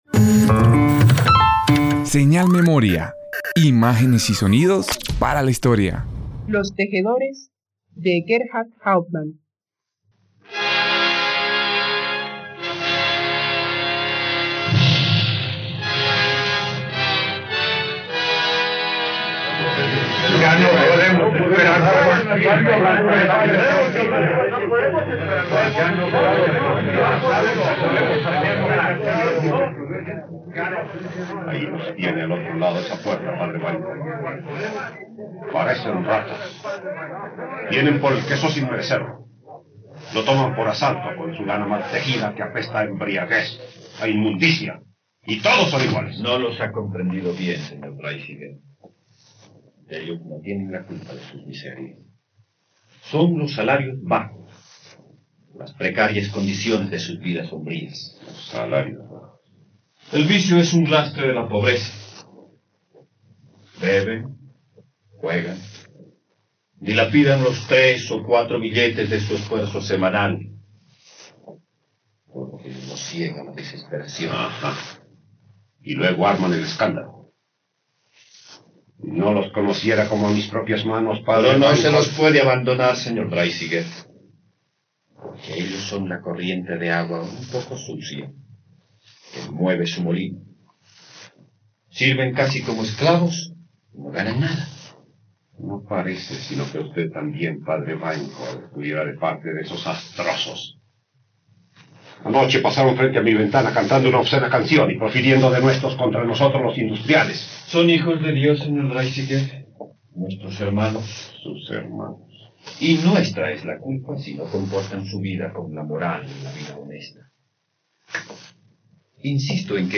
Asset ID 0 Arriba 0% Down 0% Producción Los tejedores Tags radionovela ficción historia Industria Duración 47Minutos Archivo Los tejedores_Radio.mp3 (63.63 MB)